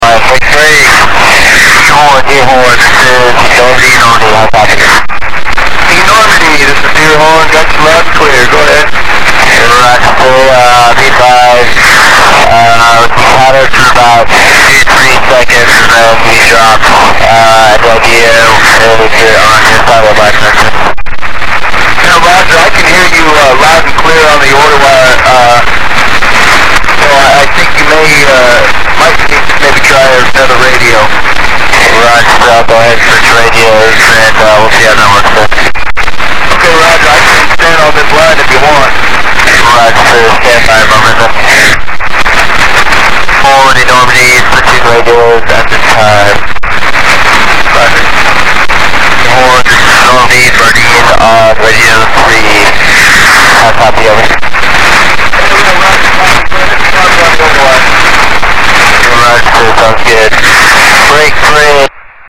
Начало » Записи » Записи радиопереговоров - NATO и союзники